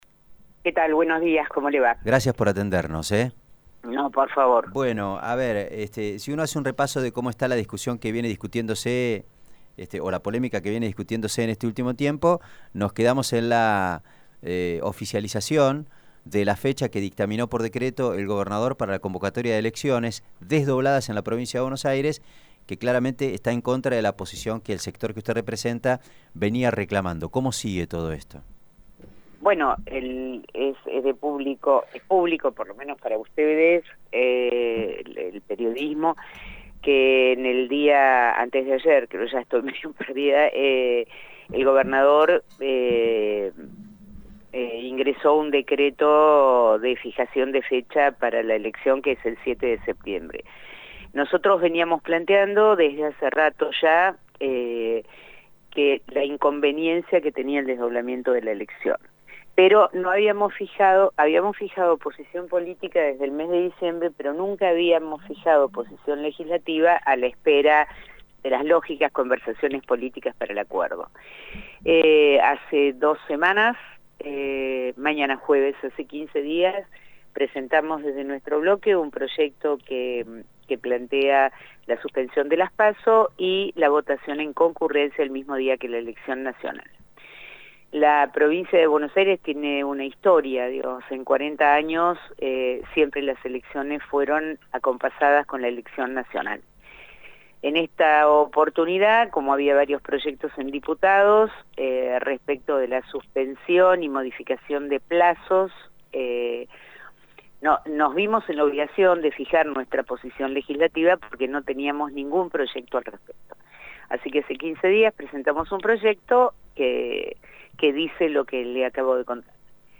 Lo confirmó la senadora bonaerense Teresa García en diálogo con FM Cielo.